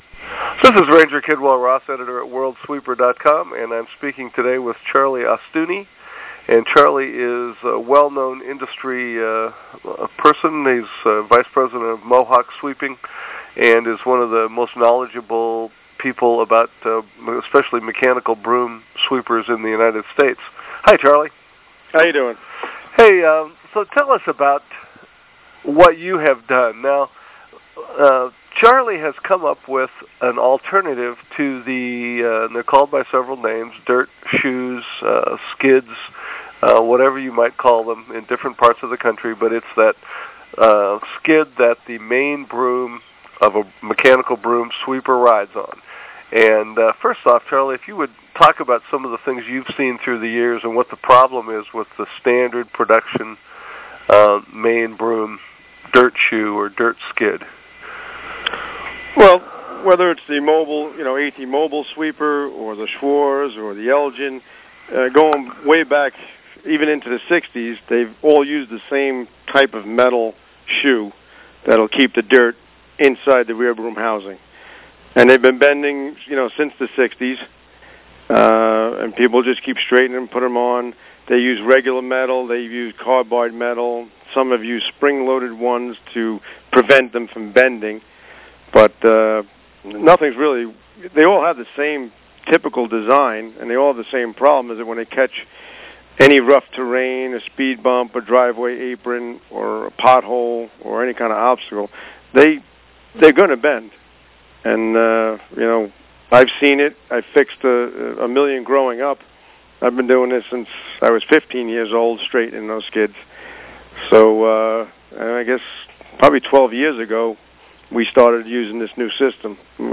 audio interview